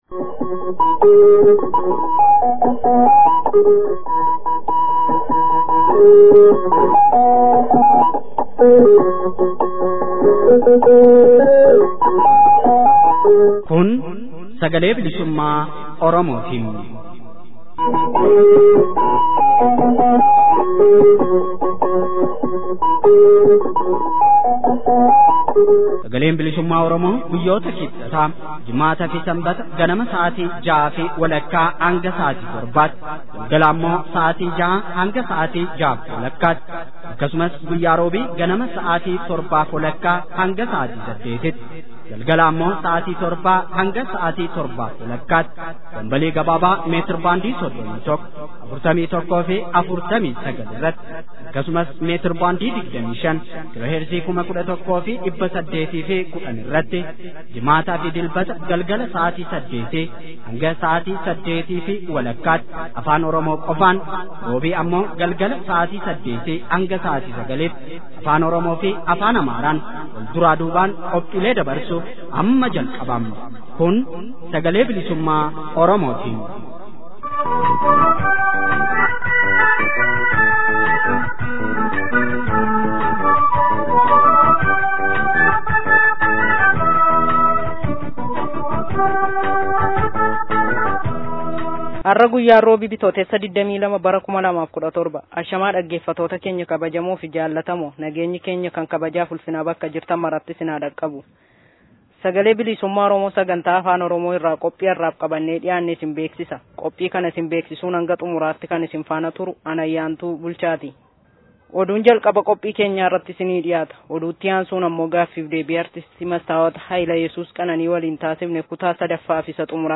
SBO Bitootessa 22,2017. Oduu, Gaaffii fi deebii